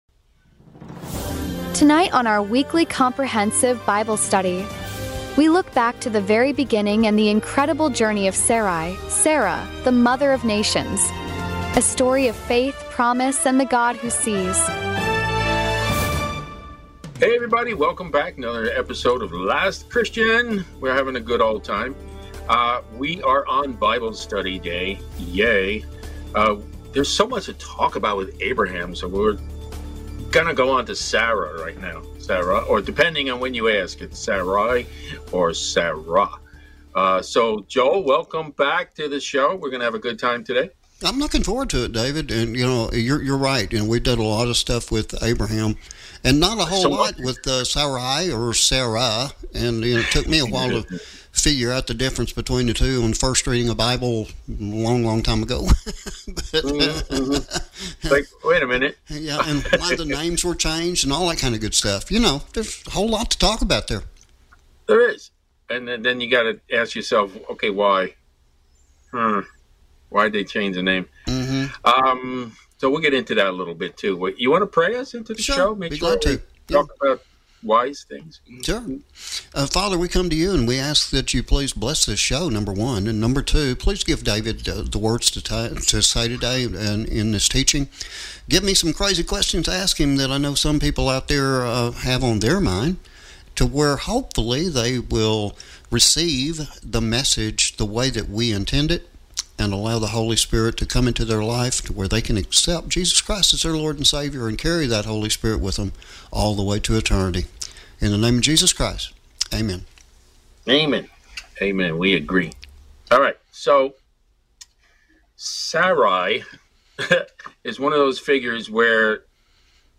A rich, powerful, and encouraging Bible study for every believer seeking to go deeper into God’s Word and understand Sarah’s prophetic role in redemptive history.